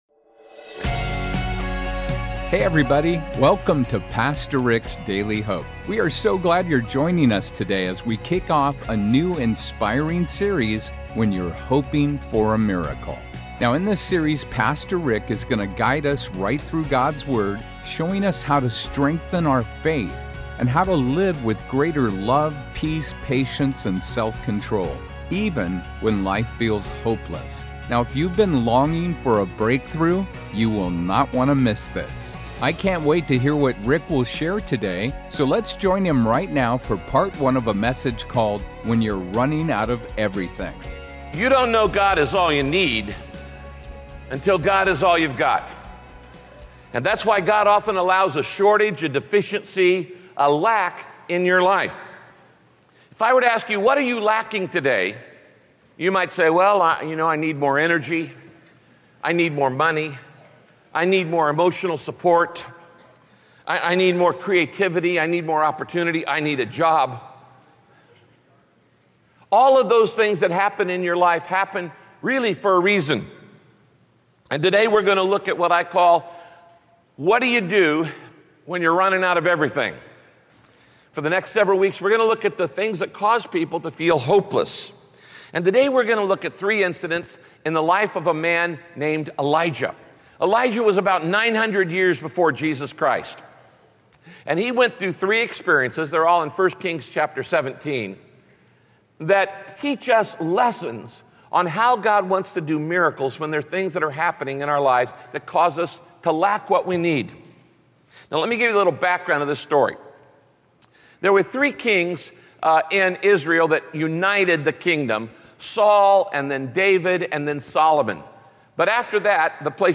Pastor Rick teaches how to follow God in faith when you’re walking through uncomfortable or uncharted territory.